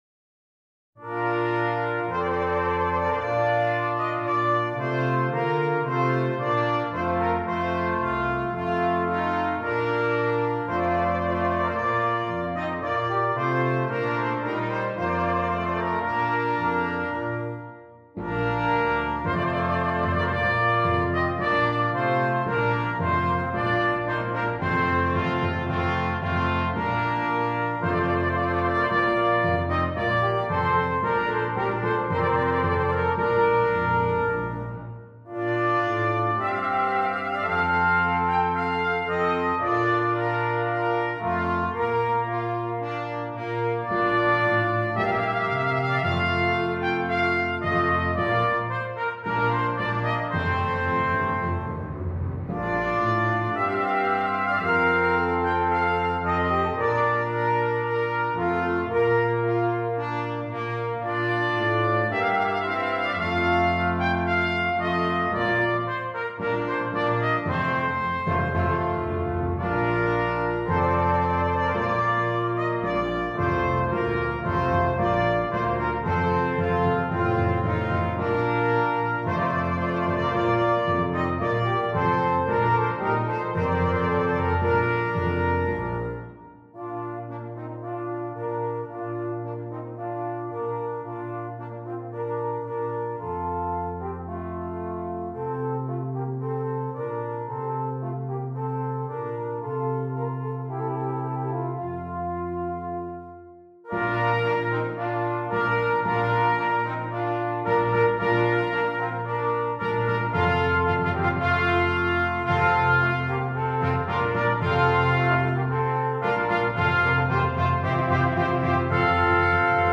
Brass Quintet